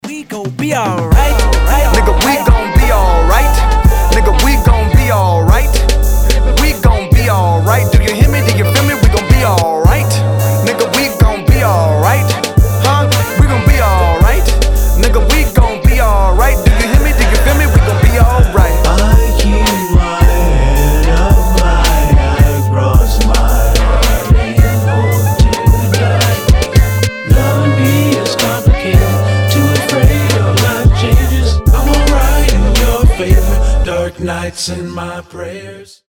• Качество: 320, Stereo
позитивные
качающие